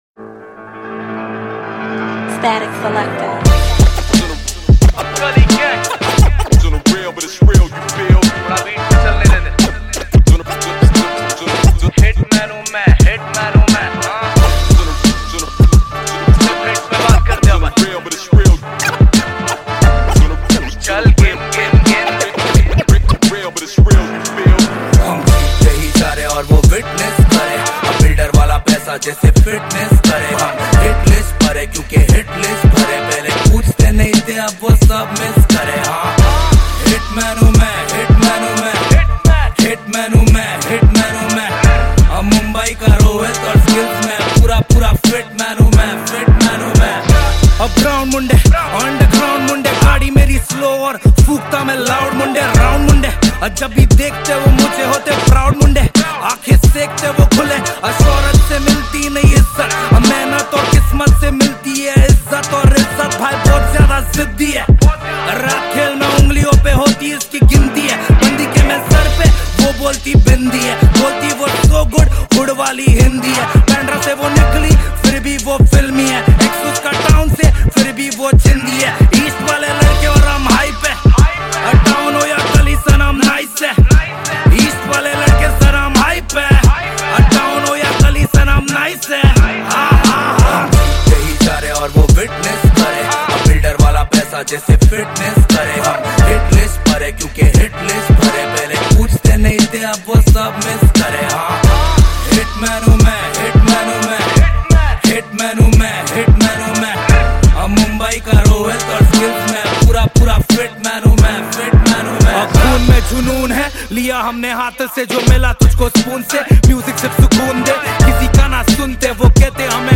Hindi Pop Album Songs 2022